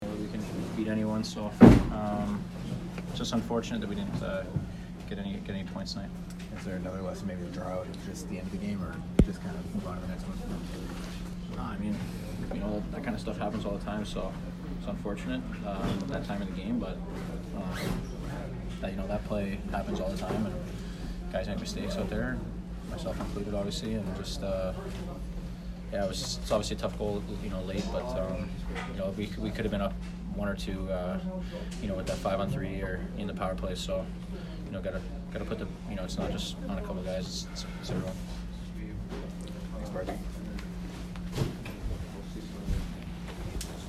Mathew Barzal post-game 11/8